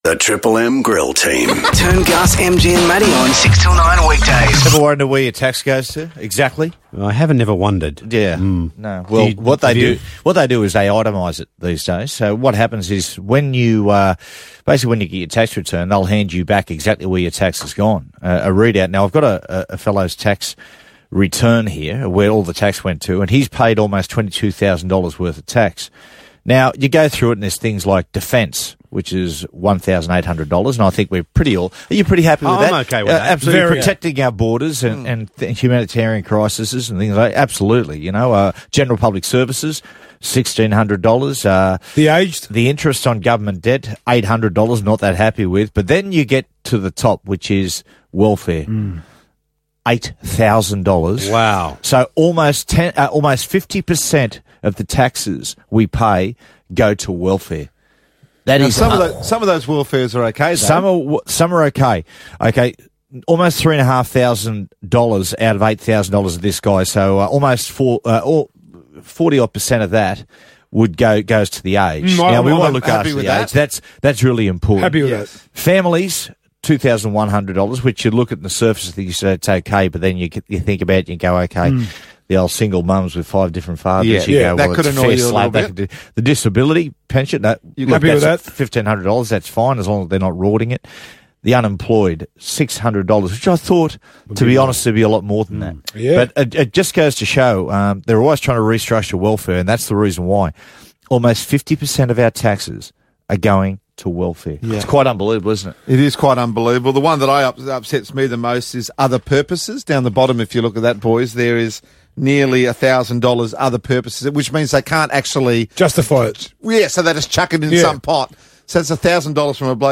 The Triple M Grill Team spoke about how the Australian Taxation Office is telling tax payers how their taxes are being spent.